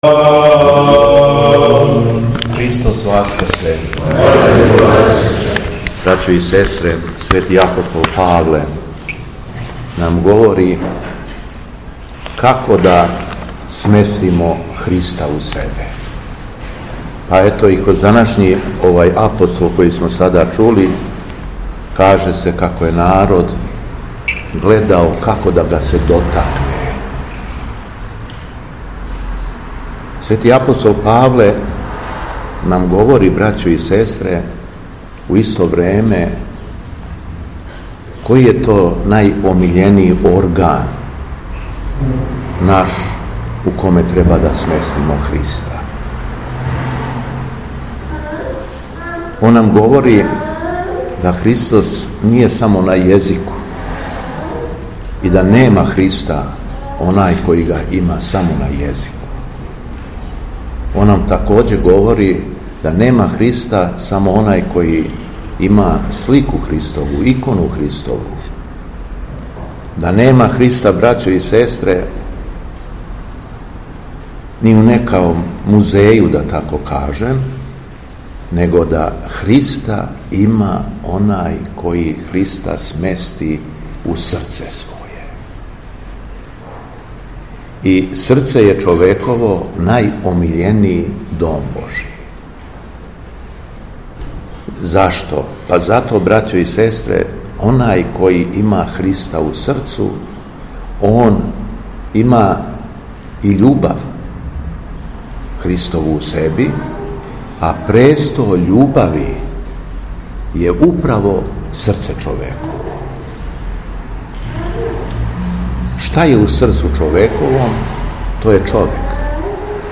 Беседа Његовог Преосвештенства Епископа шумадијског г. Јована
После прочитаног јеванђелског зачала Преосвећени Владика се обратио верном народу беседом:
У уторак, шести по Васкрсу, Његово Преосвештенство Епископ шумадијски г. Јован служио је Свету Архијерејску Литургију у храму Светог великомученика Димитрија у крагујевачком насељу Сушица уз саслужење братства овога светога храма.